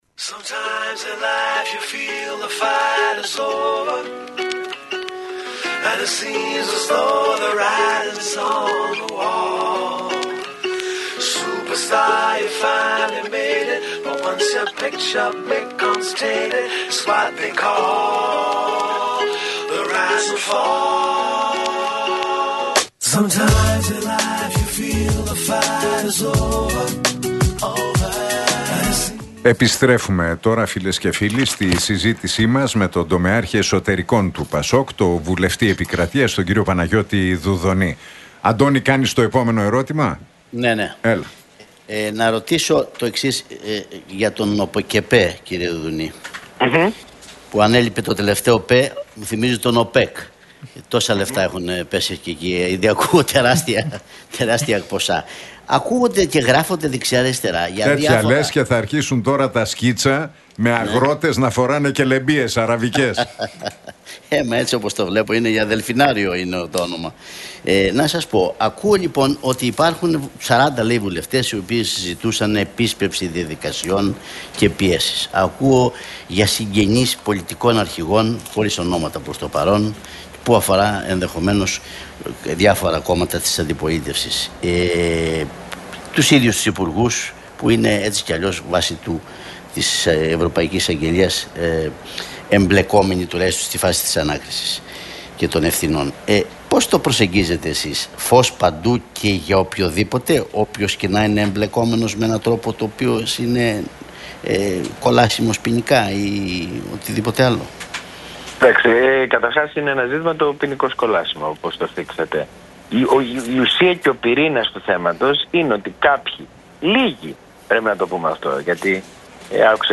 Ακούστε την εκπομπή του Νίκου Χατζηνικολάου στον ραδιοφωνικό σταθμό RealFm 97,8, την Τρίτη 24 Ιουνίου 2025.